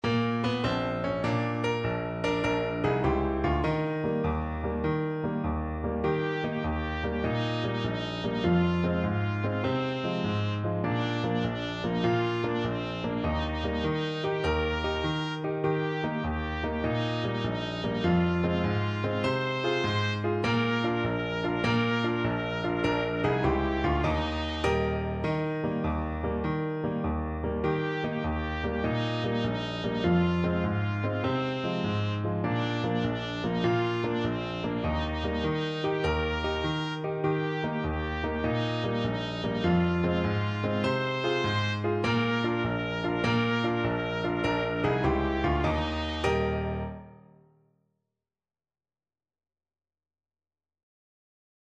Traditional Music of unknown author.
Allegro .=c.100 (View more music marked Allegro)
6/8 (View more 6/8 Music)
Bb4-C6